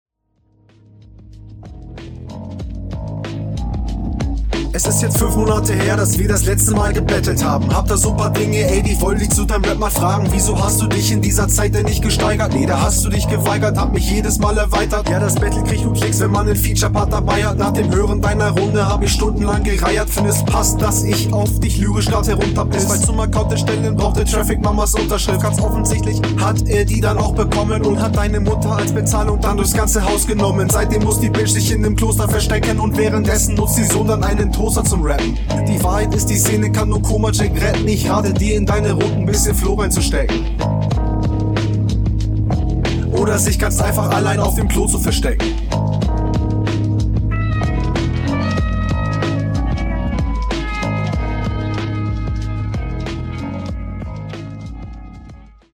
Flow ist eigentlich ok, aber ich fühl das irgendwie 0 gerade.
Der beat liegt dir und du flowst auch cool drauf.